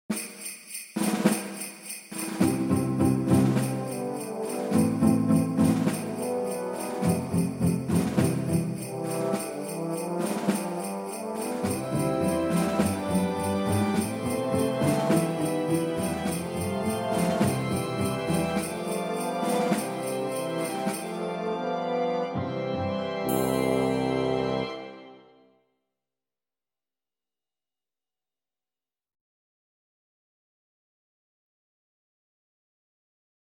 Accomp